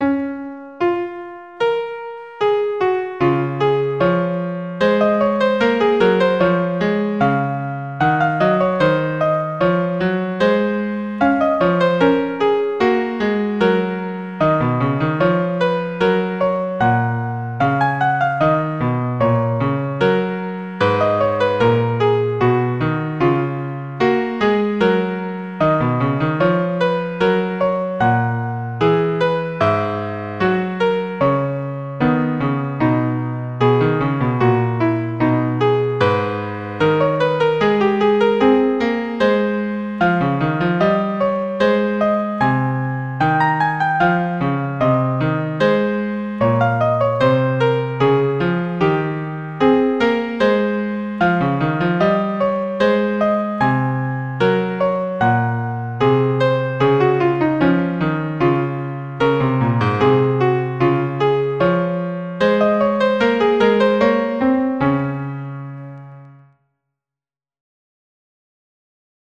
2-part invention in Db - Piano Music, Solo Keyboard - Young Composers Music Forum
2-part invention in Db